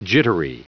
Prononciation du mot jittery en anglais (fichier audio)
Prononciation du mot : jittery
jittery.wav